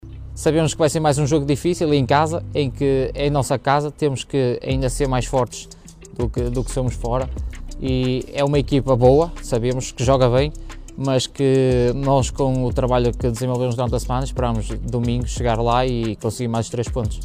em declarações à comunicação do clube em vésperas do Galos defrontarem o Moreirense.